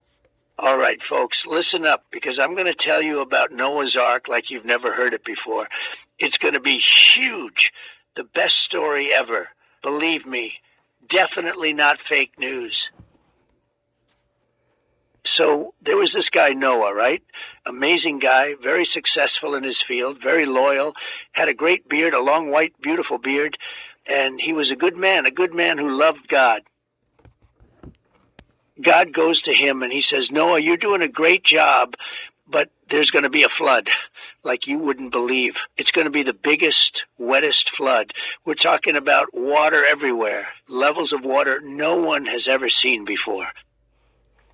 President's Bible: Hear Trump's Voice Tell Noah's Ark - Kids Bible
(LIMITED TIME SALE)Through the magic of AI technology, a unique children's book experience with biblical teachings has been created.
This 12 page book features an interactive button on each page that activates Trump's signature delivery with crystal-clear narration, making scripture stories engaging and memorable for young Christian children.